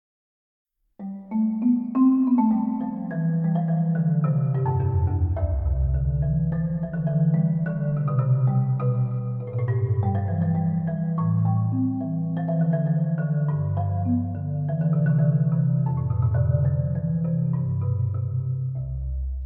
Performance Type: Solo, Arrangement/Transcription